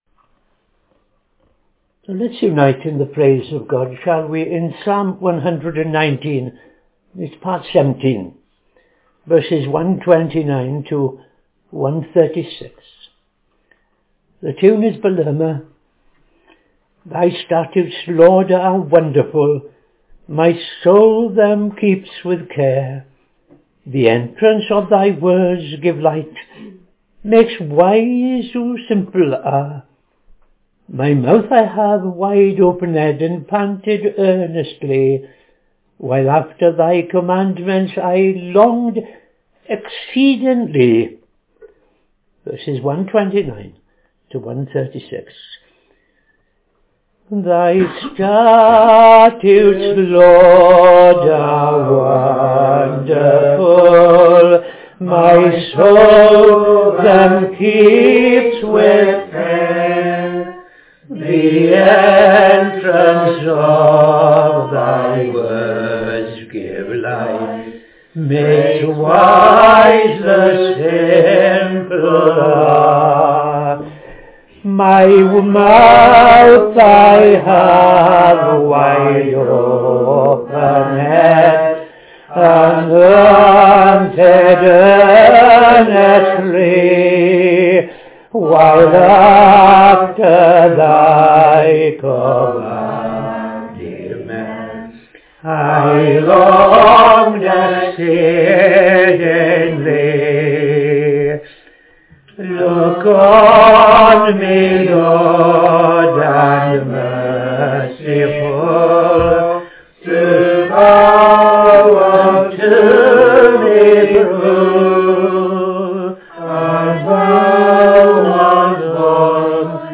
Morning Service - TFCChurch
Public Prayer followed by N.T. Reading Luke 21:34 – 22:23